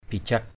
/bi˨˩-ʥak˨˩/